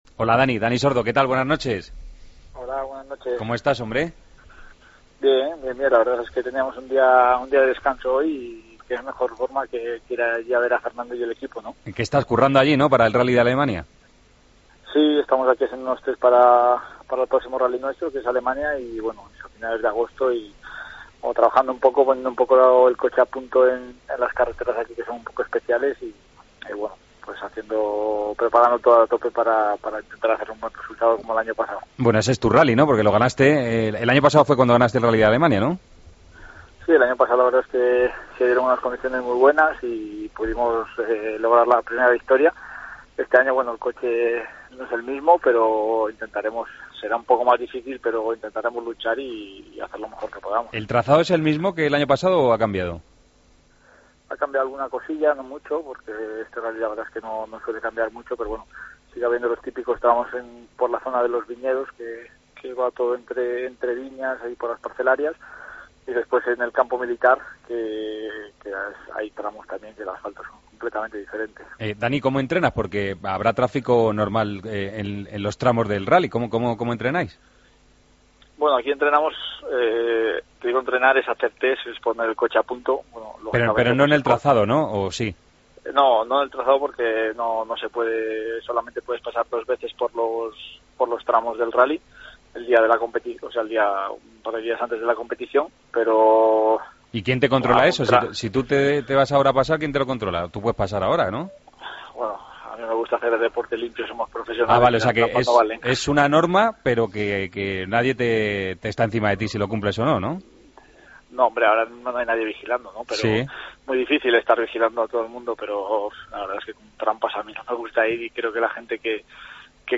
Entrevista a Dani Sordo, en El Partido de las 12